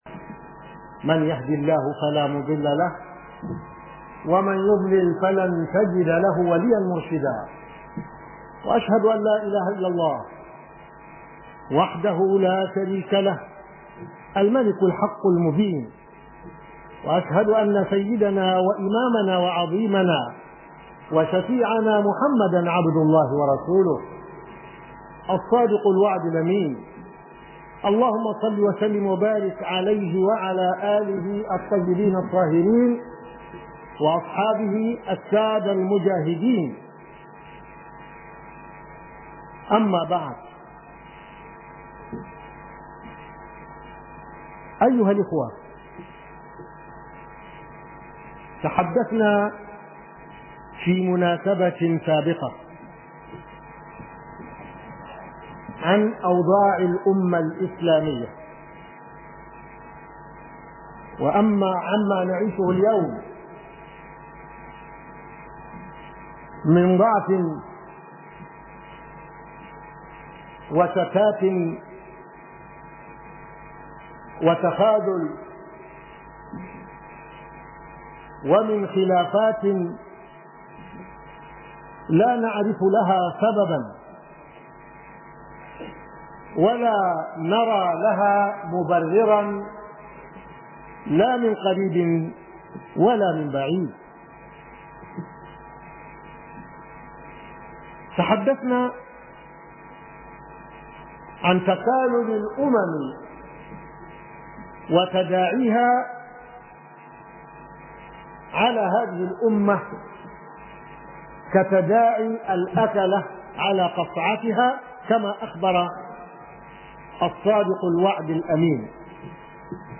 041 KHUDBAH SHARIF IBRAHIM.mp3